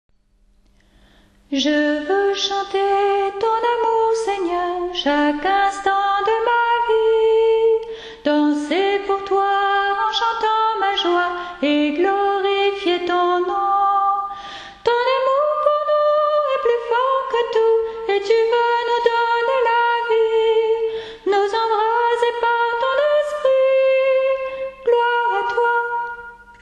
Voix chantée (MP3)COUPLET/REFRAIN
SOPRANE